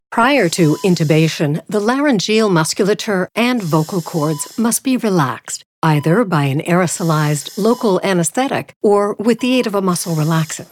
Female
Adult (30-50), Older Sound (50+)
With decades of experience, my sound is authentic, welcoming, guiding, real, nurturing, believable, and warm.
Medical Narrations
Demo's Hard To Pronounce Words